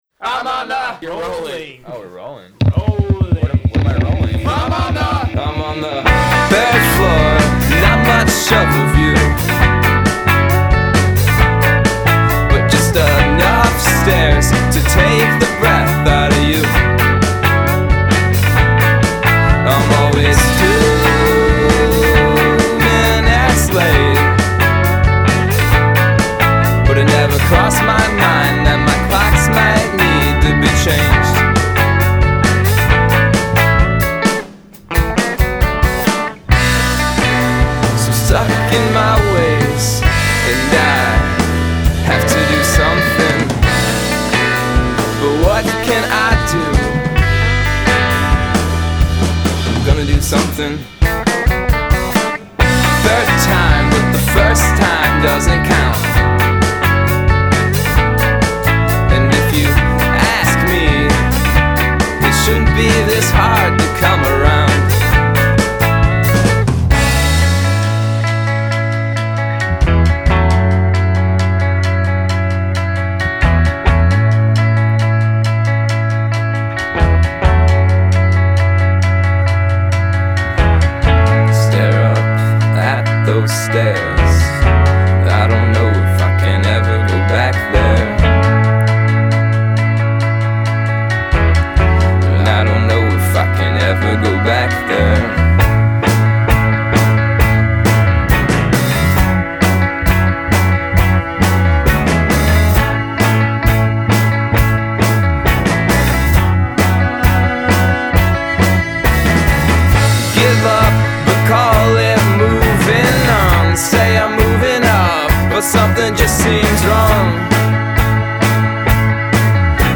smooth layers and propulsive rhythms